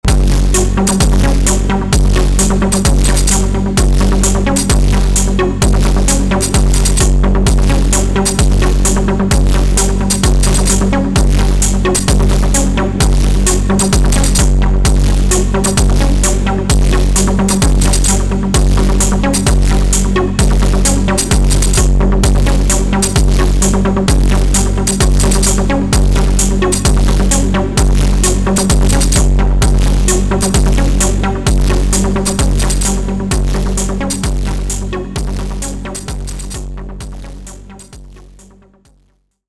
Noise in combination with overdrive, distortion and compression takes this machine to the next level.
In this example, I use the acoustic kick, the chip synth for the bass line (with Euclidean mode), the raw synth for the noise rumble and the hi-hats withe the labs.